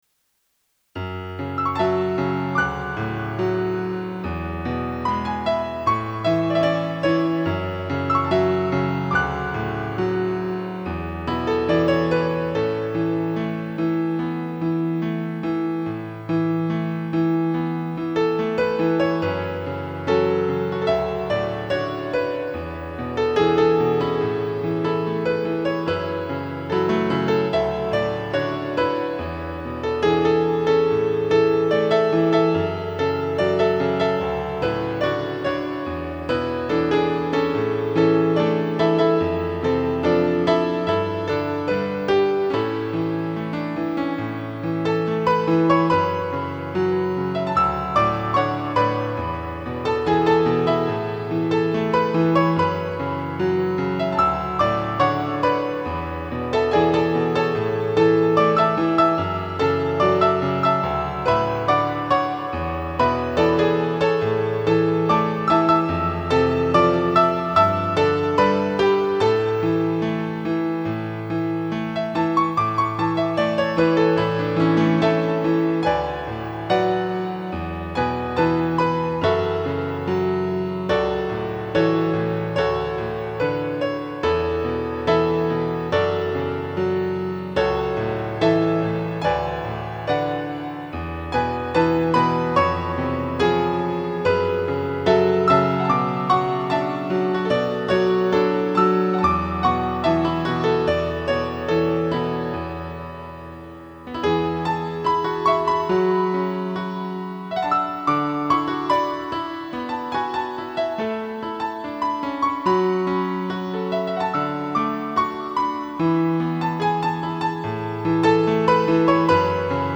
静かな決意を感じさせる曲でもあります。
全体的にオクターブのメロディで和音が連続する難しい構成となっています。